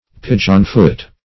pigeonfoot - definition of pigeonfoot - synonyms, pronunciation, spelling from Free Dictionary Search Result for " pigeonfoot" : The Collaborative International Dictionary of English v.0.48: Pigeonfoot \Pi"geon*foot`\, n. (Bot.) The dove's-foot geranium ( Geranium molle ).